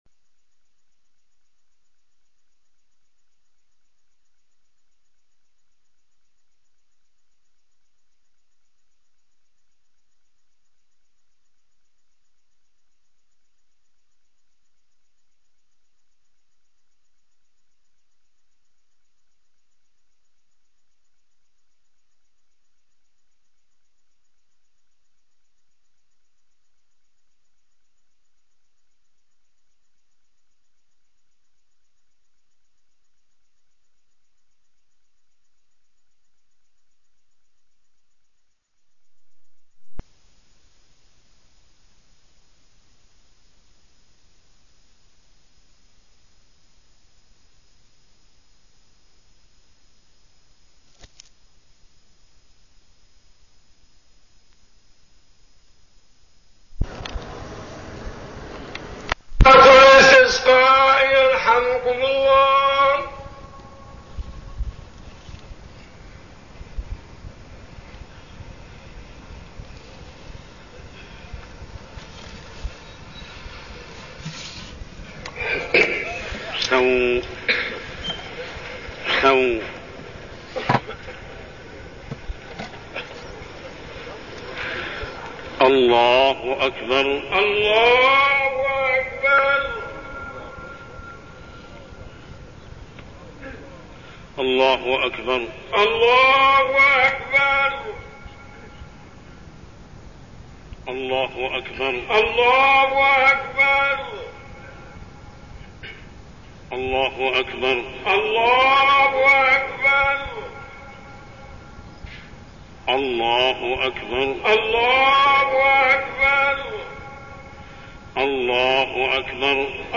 تاريخ النشر ٢٧ شعبان ١٤١٢ هـ المكان: المسجد الحرام الشيخ: محمد بن عبد الله السبيل محمد بن عبد الله السبيل الإقبال على الله في كل الأحوال